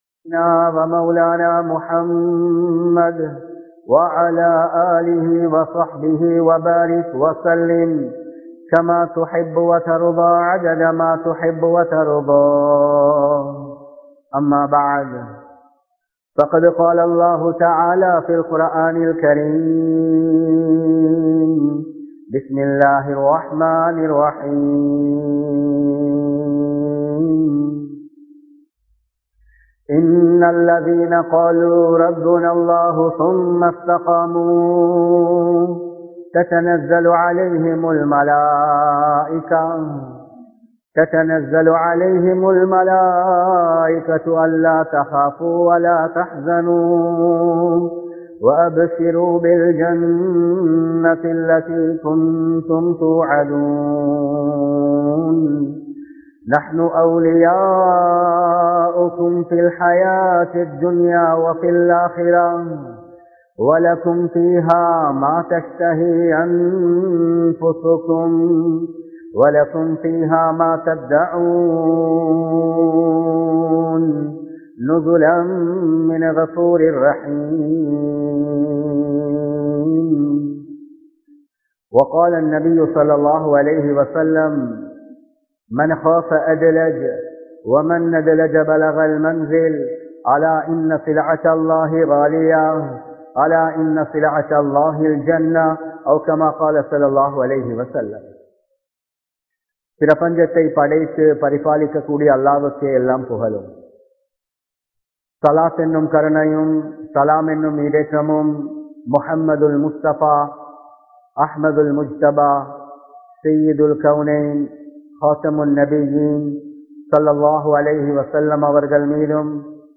மஸ்ஜிதை நேசிப்போம் | Audio Bayans | All Ceylon Muslim Youth Community | Addalaichenai